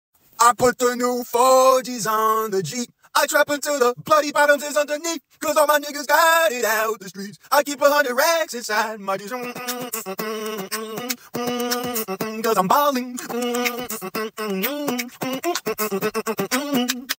Ballin-beatbox-sound-djlunatique.com_.mp3